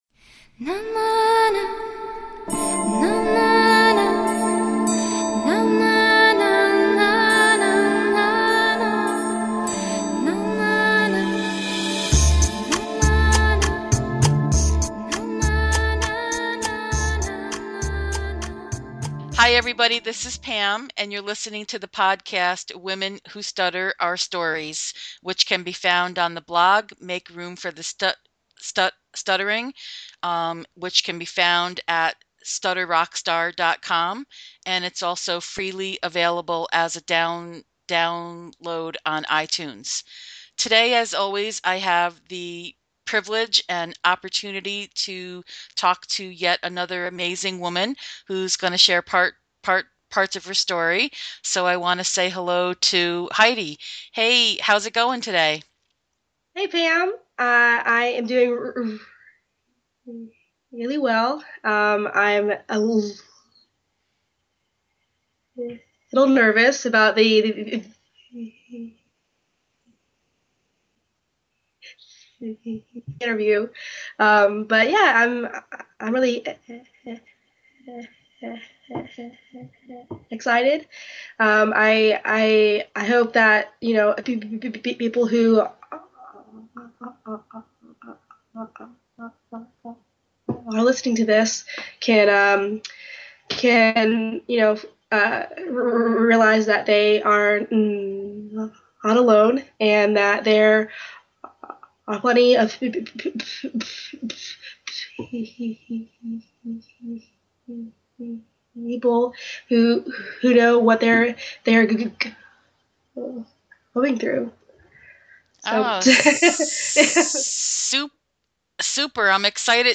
Listen in to a meaningful conversation about guilt.